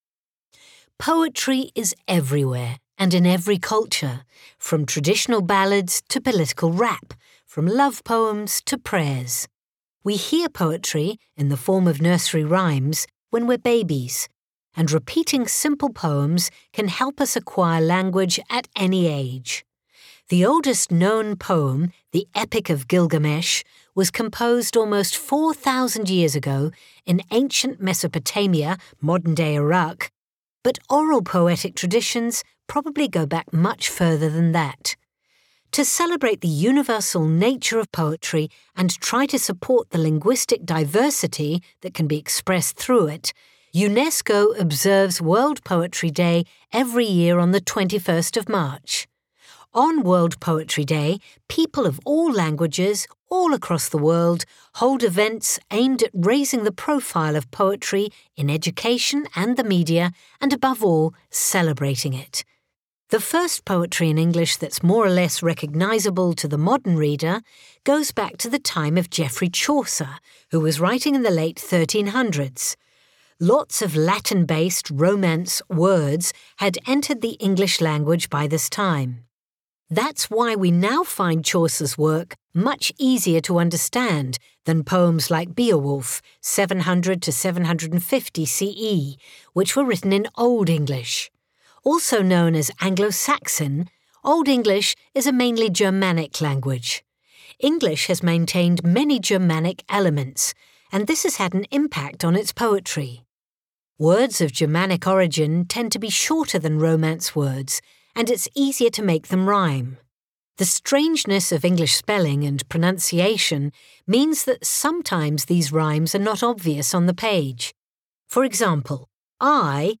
Speaker (UK accent)
Speaker (American accent)